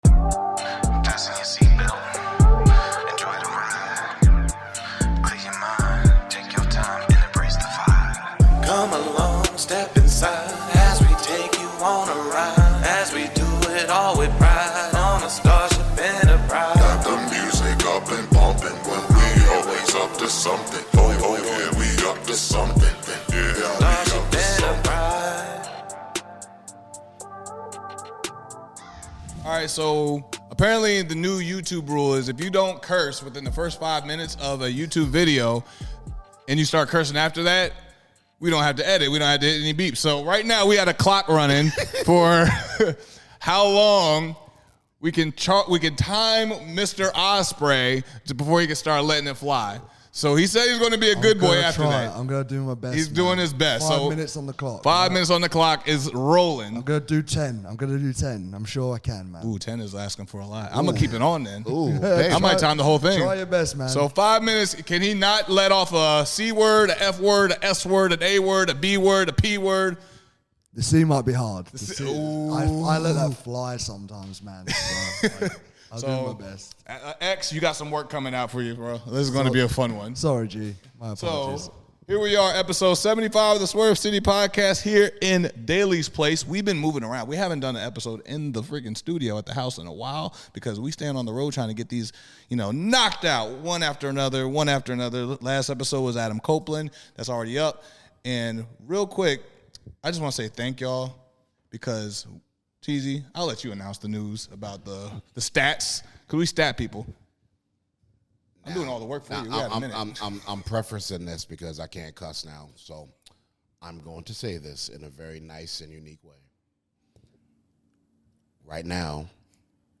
The swerve city podcast interviews the billy goat will ospreay. We speak about Batman, His future in wrestling, and more!